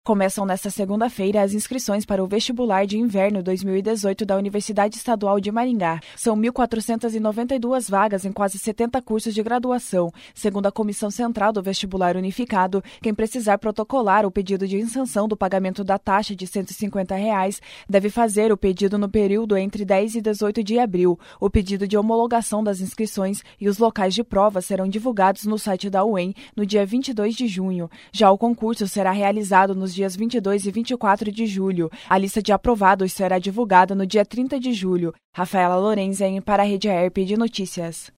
09.04 – BOLETIM/SEM TRILHA – UEM abre inscrições para Vestibular de Inverno 2018